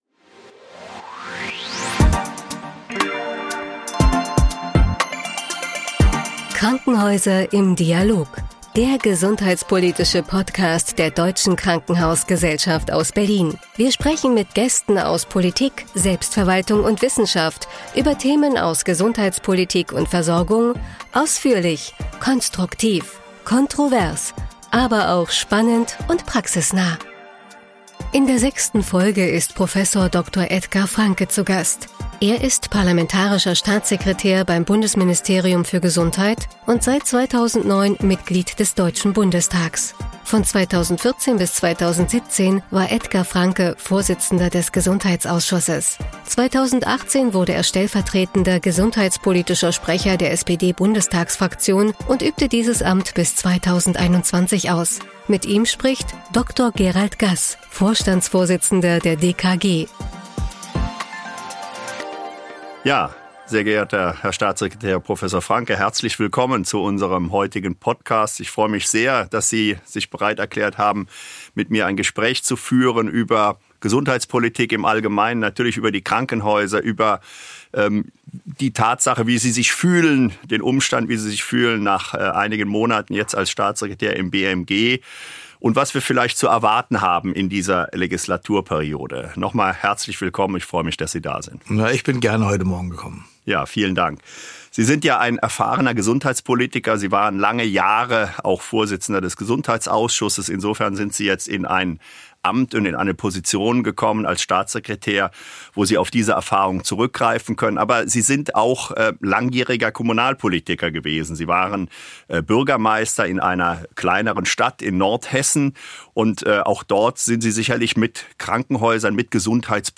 Beschreibung vor 3 Jahren In der 6. Folge ist Prof. Dr. Edgar Franke, Parlamentarischer Staatssekretär im Bundesministerium für Gesundheit, zu Gast.